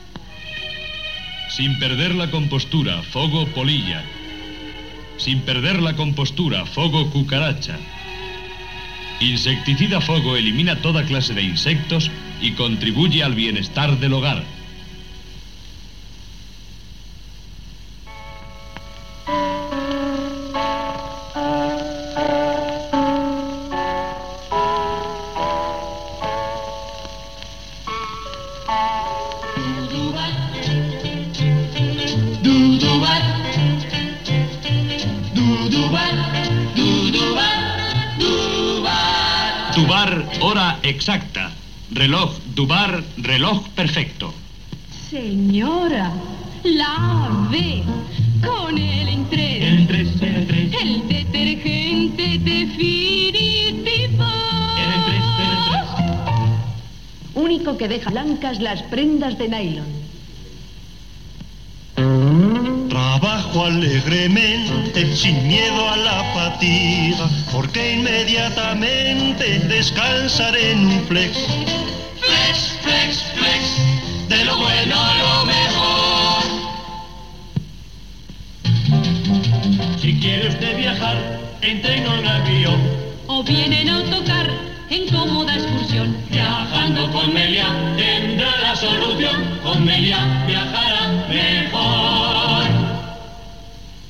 Bloc publicitari